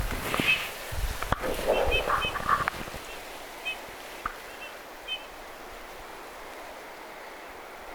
punatulkku tuli lähelle tutkimaan kulkijaa
punatulkku_tuli_katsomaan_lintuharrastajaa_lahelta.mp3